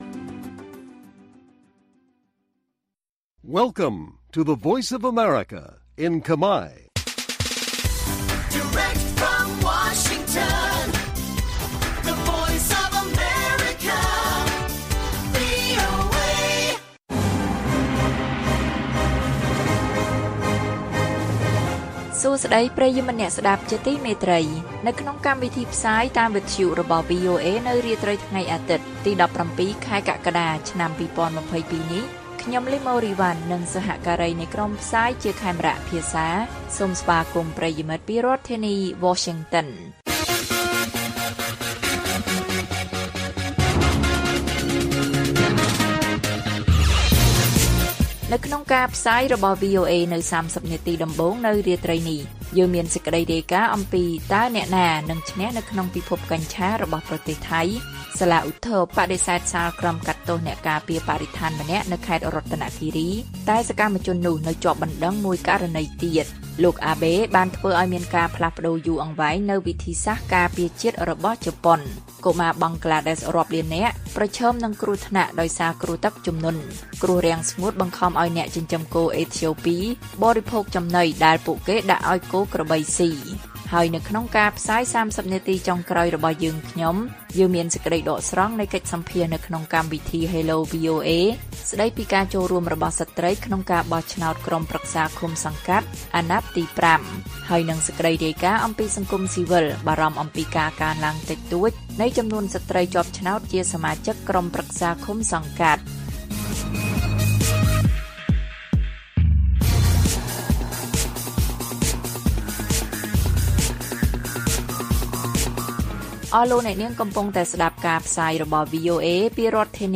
សេចក្តីដកស្រង់នៃបទសម្ភាសន៍នៅក្នុងកម្មវិធី Hello VOA ស្តីពី«ការចូលរួមរបស់ស្រ្តីក្នុងការបោះឆ្នោតក្រុមប្រឹក្សាឃុំសង្កាត់អាណត្តិទី៥»និងព័ត៌មានផ្សេងទៀត៕